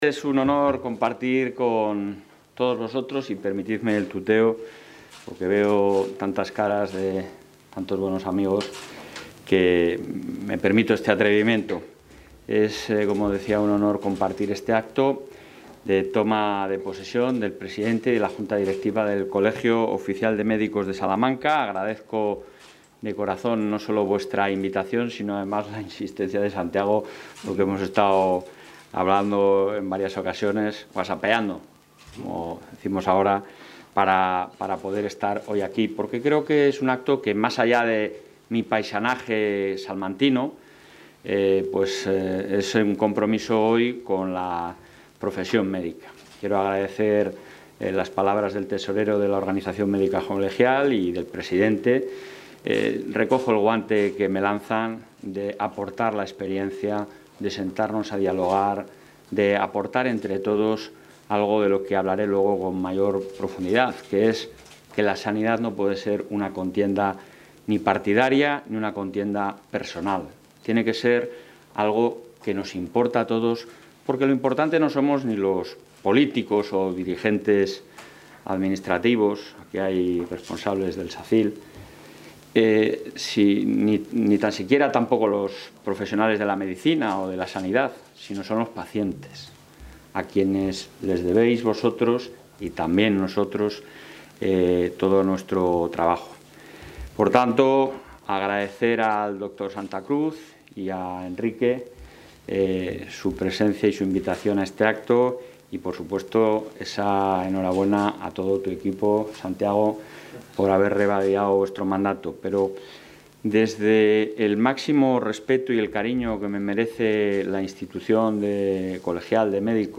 Intervención del presidente de la Junta.
Toma posesión nueva directiva Colegio Oficial Médicos Salamanca